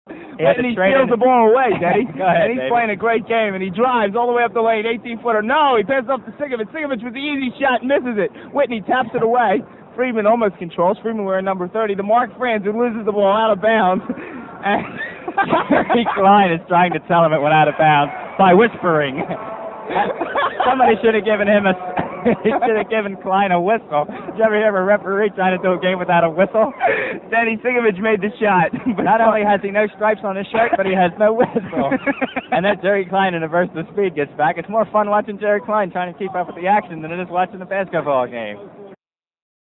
The game took place in Philadelphia's famous Palestra, at half-time of a real game between Temple and Penn State.
For that, we have the actual play-by-play audio.
Cut 5 (:40) is more game action, although our announcing team seems distracted.